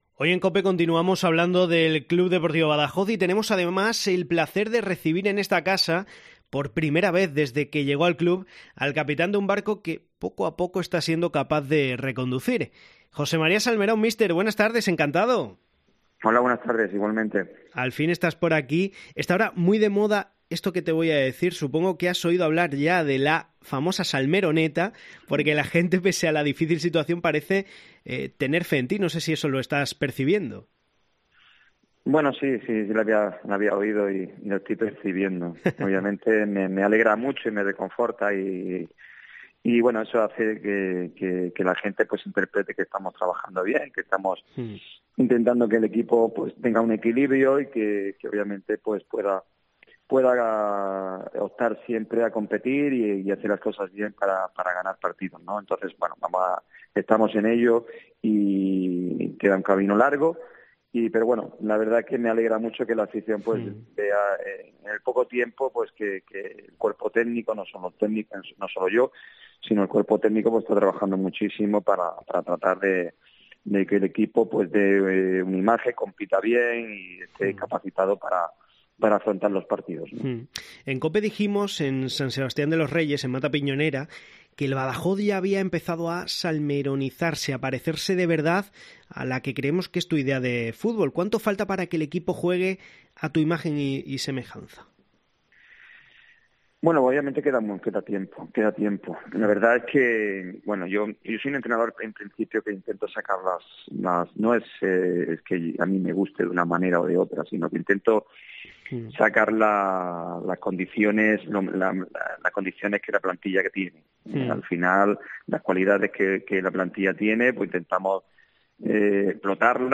Su entrevista, en titulares